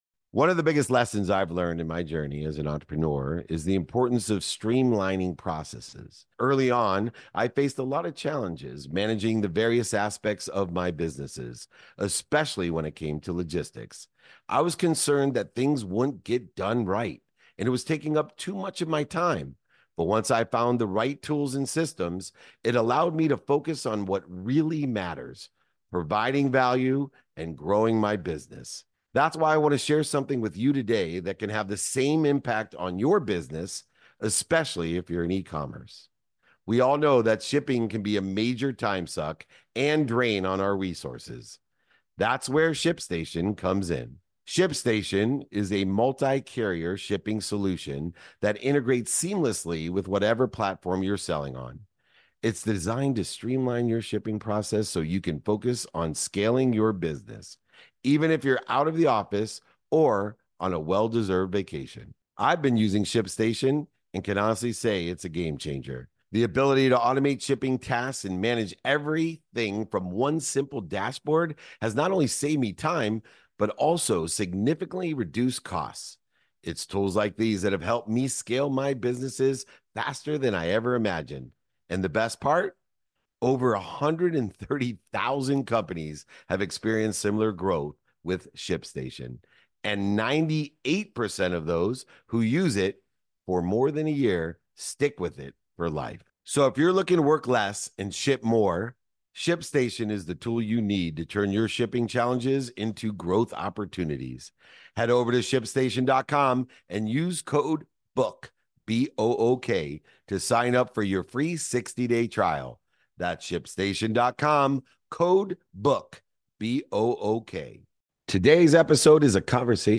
In today's episode, I sit down with Marcellus Wiley, former NFL defensive end and Pro Bowl selection, to discuss his journey from the football field to becoming a prominent sportscaster and philanthropist. Marcellus shares how his early experiences in Compton shaped his drive to succeed and his belief in conditioning the mind and body to overcome life's challenges.